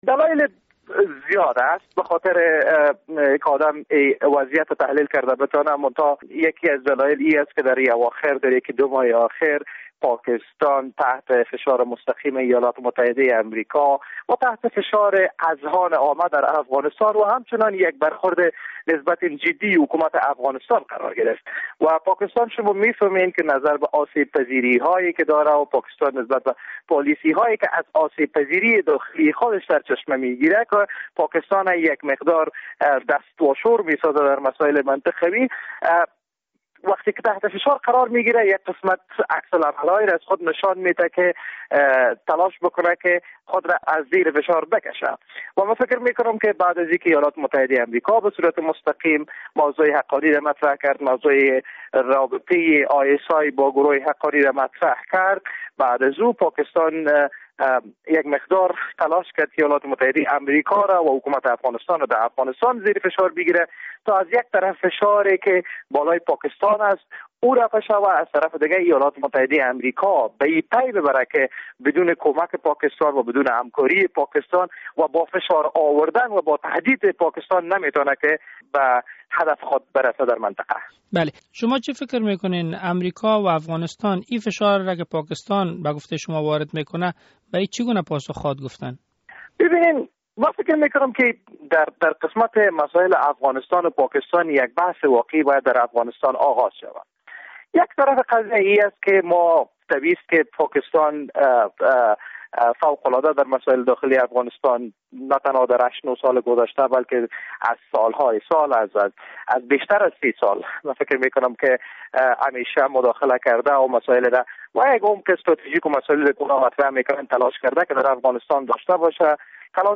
مصاحبه با وحید عمر در مورد علت افزایش حملات طالبان